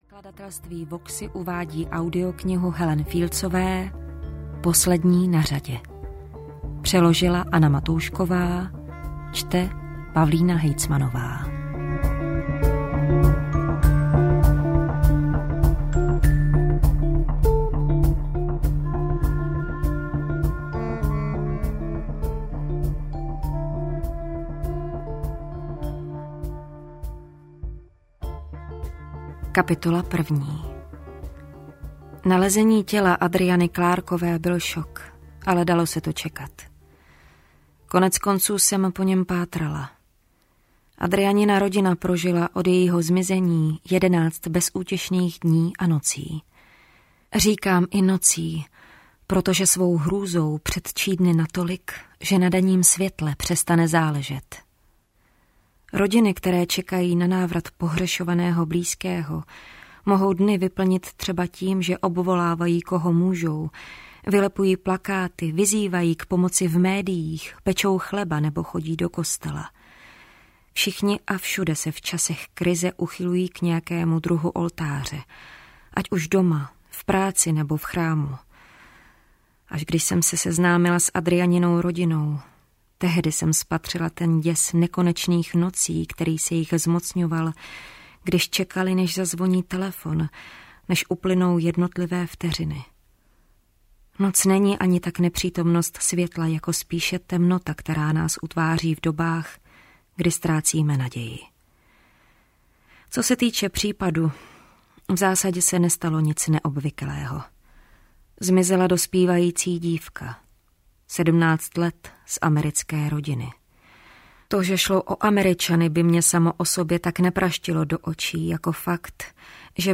Poslední na řadě audiokniha
Ukázka z knihy